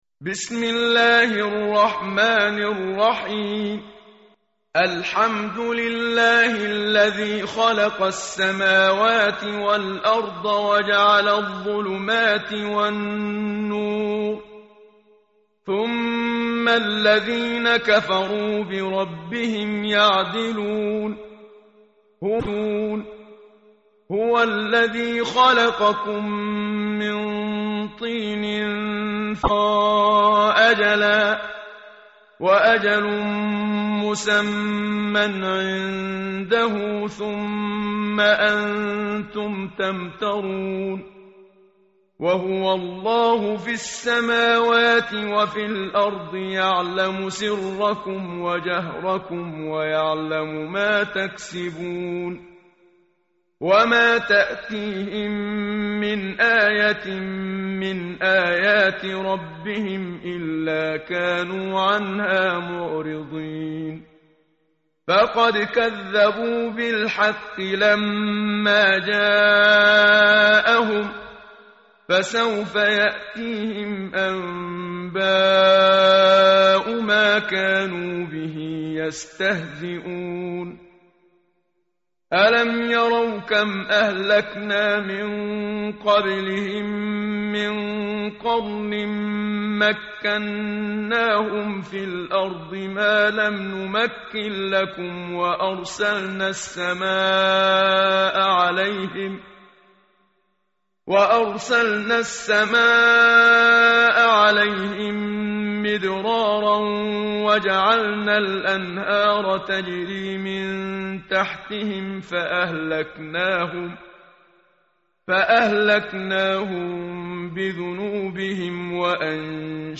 tartil_menshavi_page_128.mp3